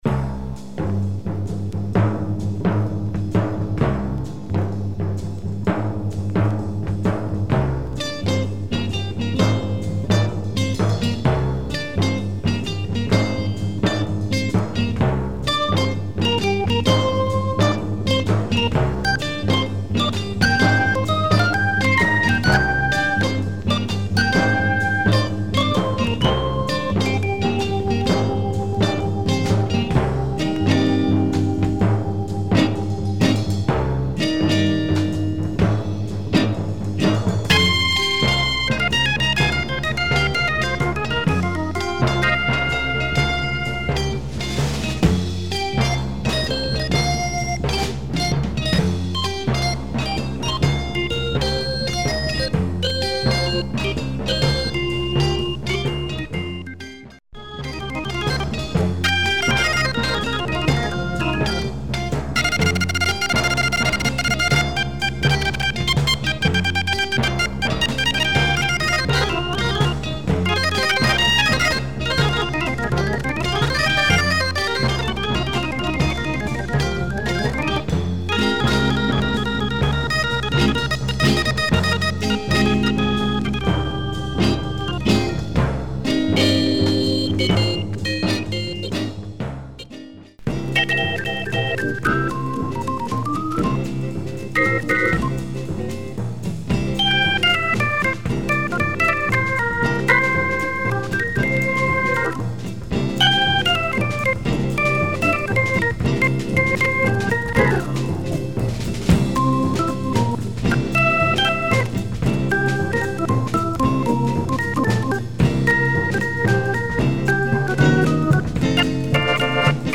Italian Library
suspense / cinematic jazz